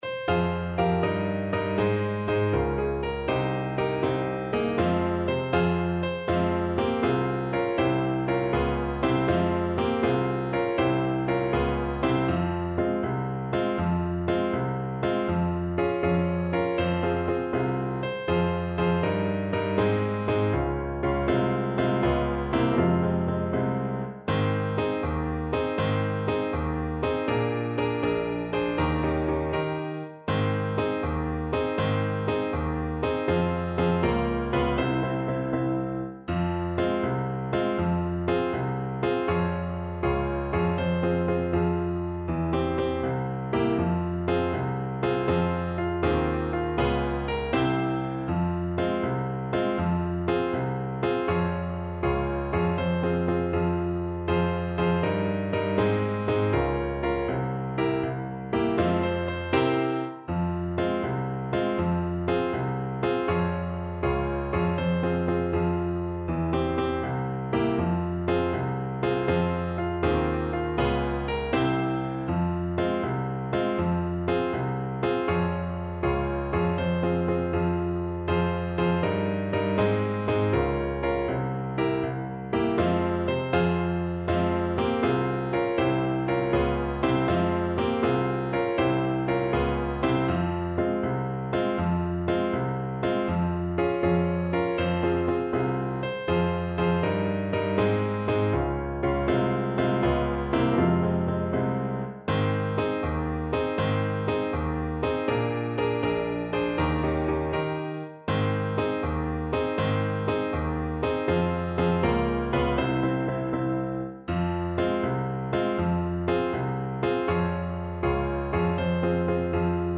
Brightly, but not too fast
6/8 (View more 6/8 Music)
Classical (View more Classical French Horn Music)